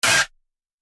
I HEAR A BELL I HEAR A BELL I HEAR A BELL I HEAR A BELL